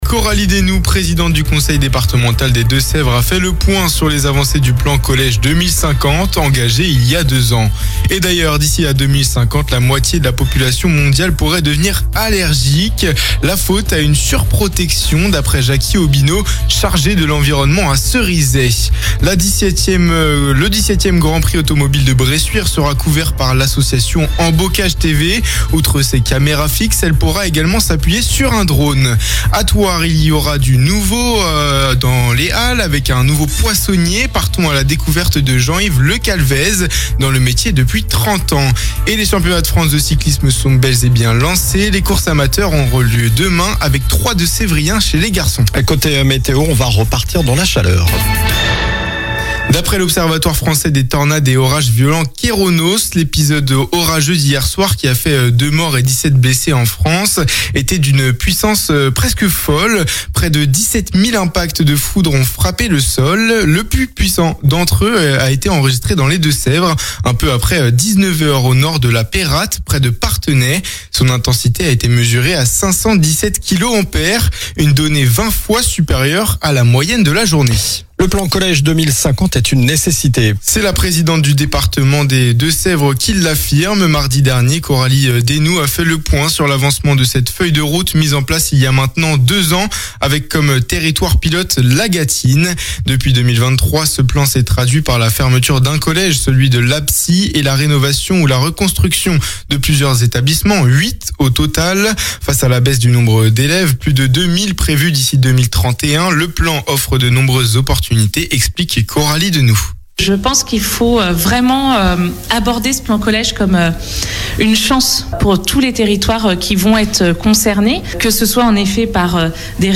Journal du jeudi 26 juin (soir)